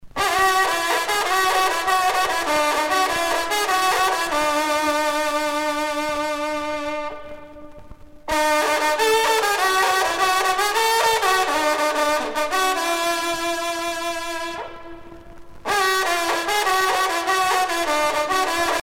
trompe - fanfare - lieux-dits
circonstance : vénerie
Pièce musicale éditée